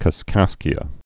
(kəs-kăskē-ə)